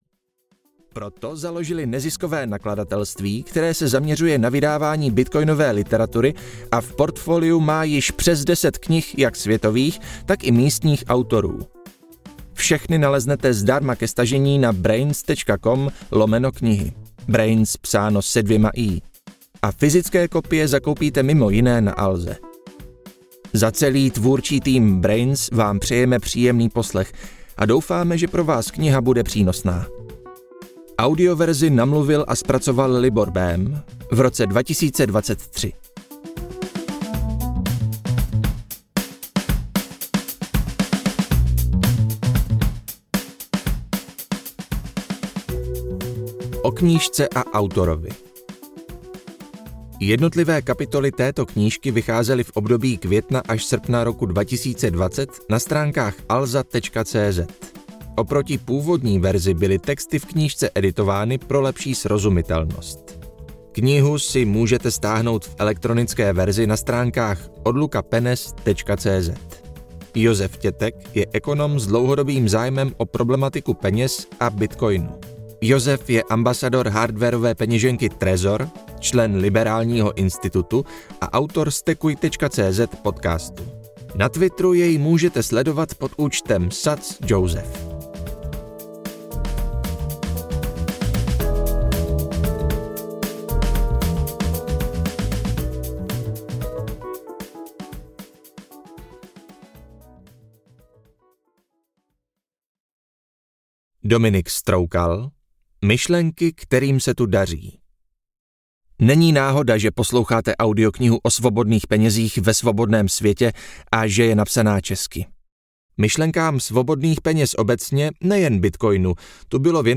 Ukázka z knihy
bitcoin-odluka-penez-od-statu-audiokniha